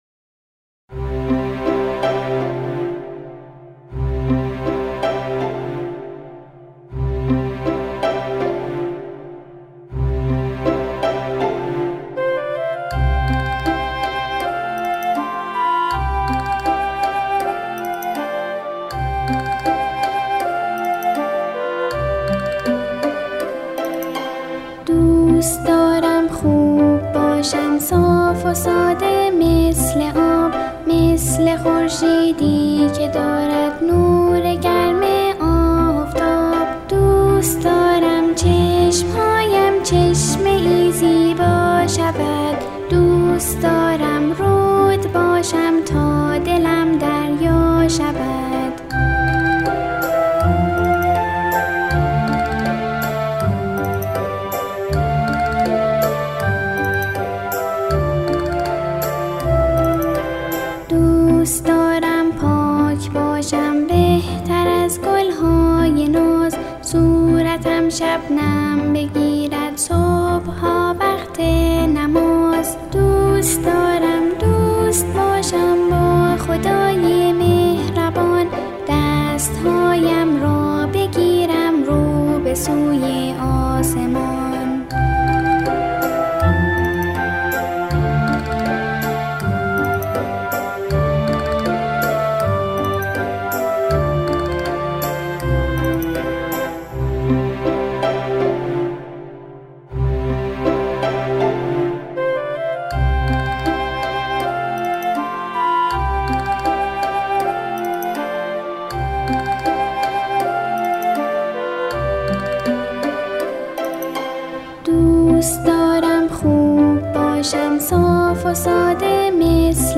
با صدای تک خوان کودک